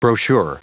Prononciation du mot brochure en anglais (fichier audio)
Prononciation du mot : brochure